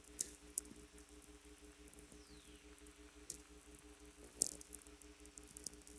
Spring Brook Township, Pennsylvania